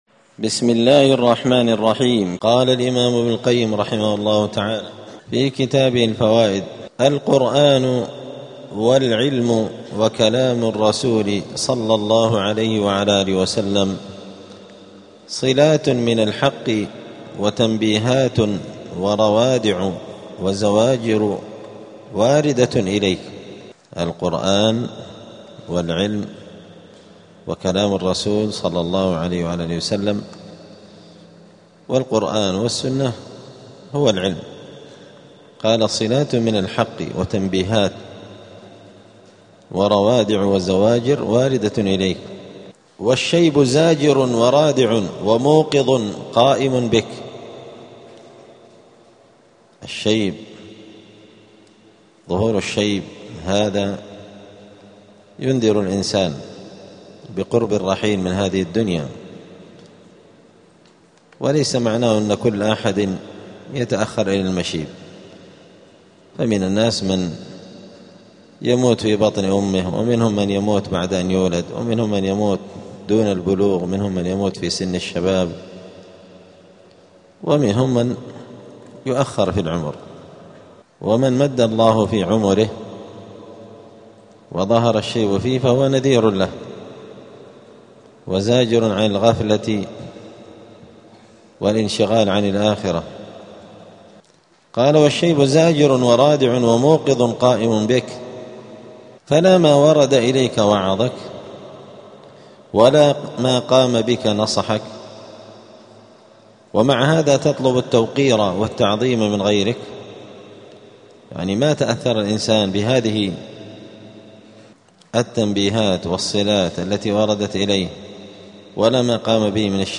*الدرس الثامن بعد المائة (108) {ﻓﺎﺋﺪﺓ ﻋﻨﺪ اﻟﻌﺎﺭﻓﻴﻦ ﺃﻥ اﻻﺷﺘﻐﺎﻝ ﺑﺎﻟﻤﺸﺎﻫﺪﺓ ﻋﻦ اﻟﺒﺮ ﻓﻲ اﻟﺴﻴﺮ ﻓﻲ اﻟﺴﺮ ﻭﻗﻮﻑ}.*
دار الحديث السلفية بمسجد الفرقان قشن المهرة اليمن